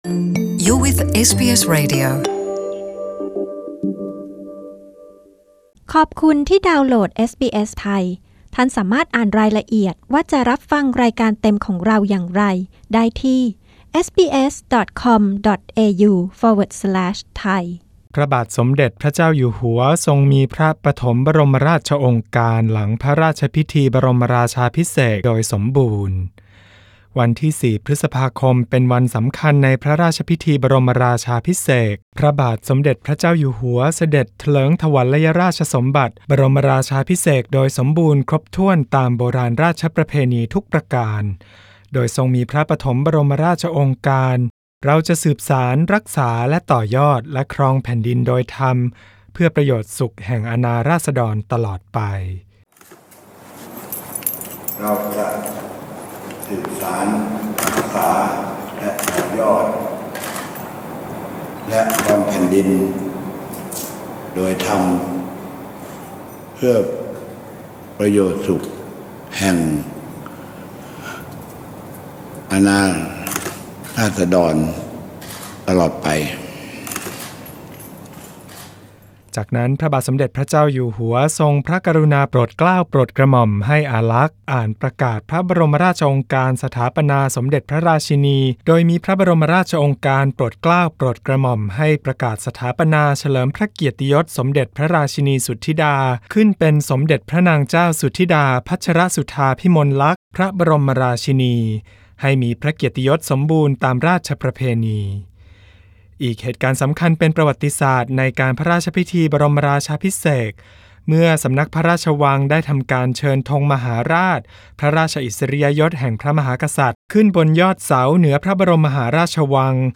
ฟังรายงานโดยละเอียดพระราชพิธีบรมราชาภิเษก พุทธศักราช 2562 ซึ่งได้จัดขึ้นโดยสมบูรณ์ตามโบราณราชประเพณีและพระราชนิยม โดยในช่วงกลางนี้มีขึ้นตั้งแต่วันเสาร์ที่ 4 พฤษภาคม ถึงวันจันทร์ที่ 6 พฤษภาคม